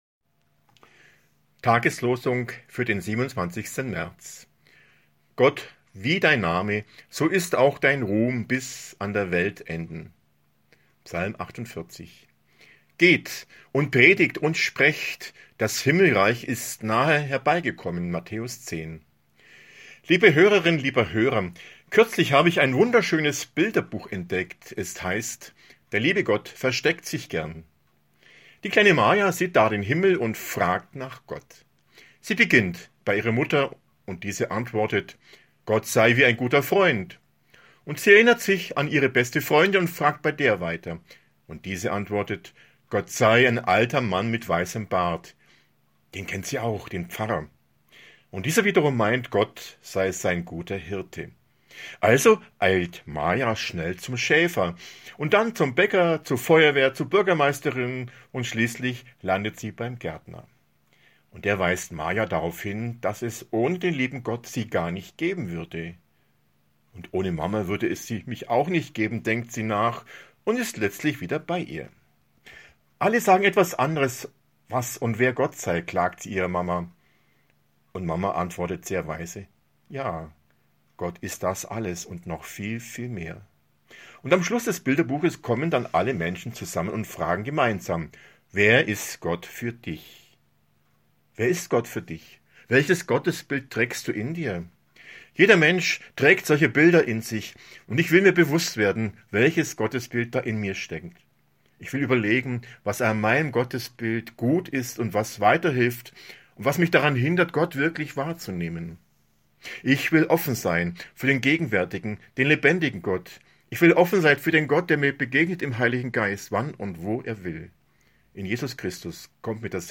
Impuls zur Tageslosung